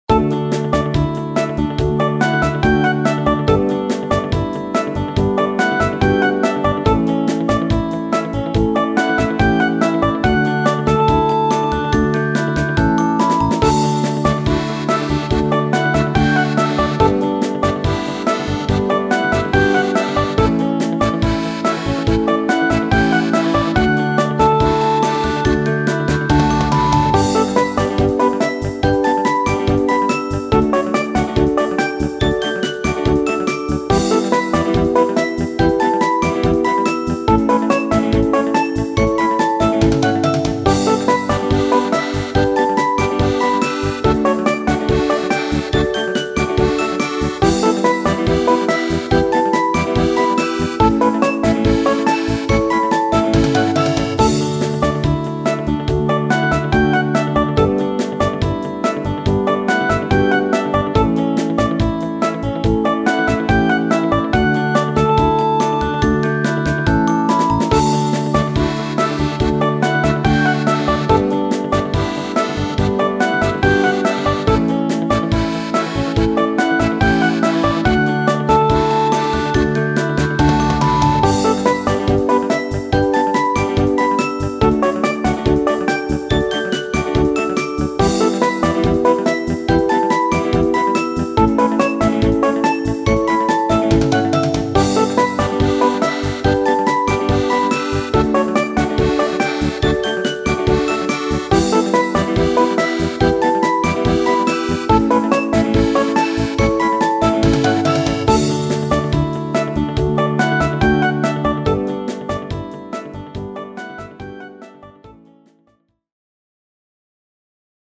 イメージ：明るい ワクワク   カテゴリ：RPG−街・村・日常